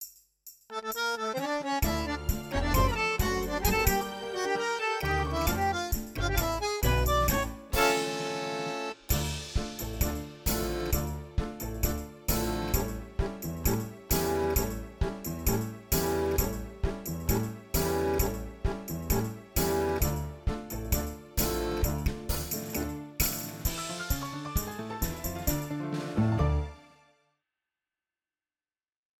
4 styles demo
Drums from Toontrack Superior Drummer 2
Percussions from Musyng Kite GM.sfpack
Accordion from Accordions Library for Kontakt. All the rest from sampled Motif XF.